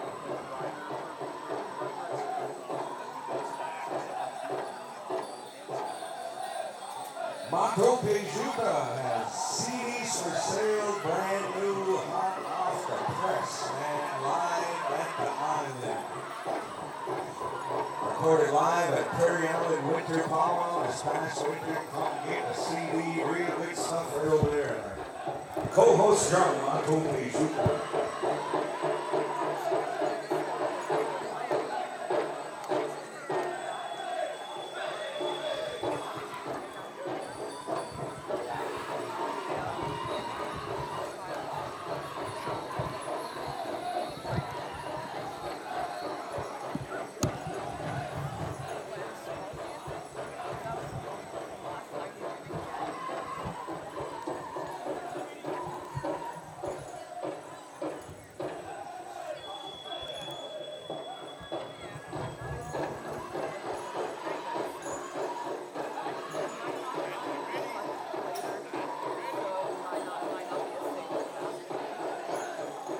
Menominee Homecoming Powwow August 2023